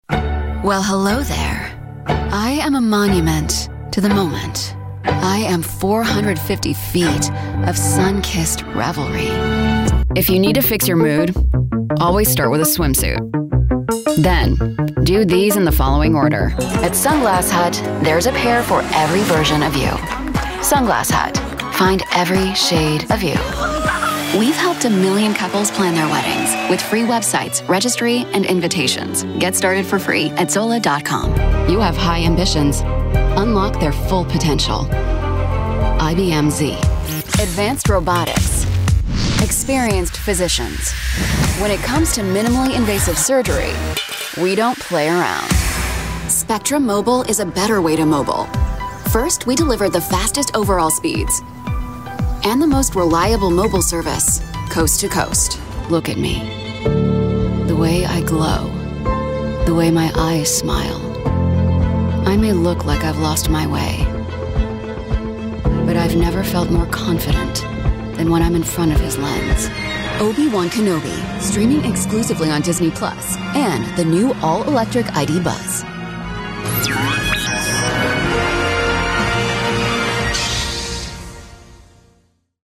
From witty and wry to warm and compassionate, I've got you.
2023 Commercial Demo